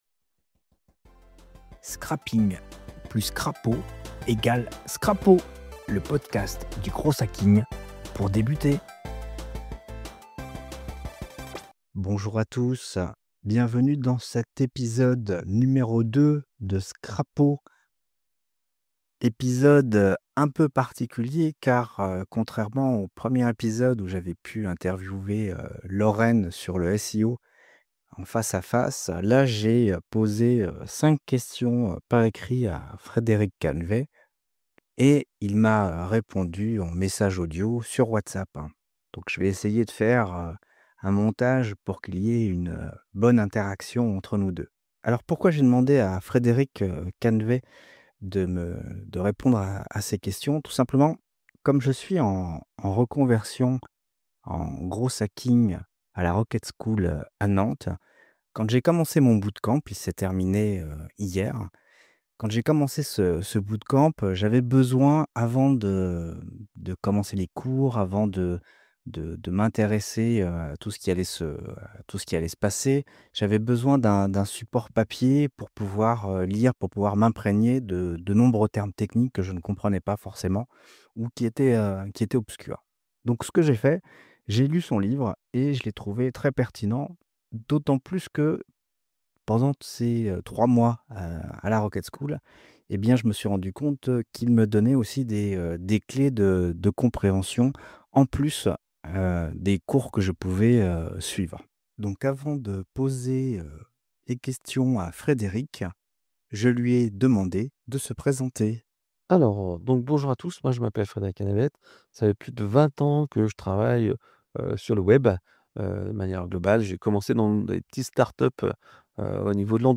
C'est quoi le growth hacking ? - Interview pour le Scrapaud